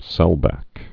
(sĕlbăk)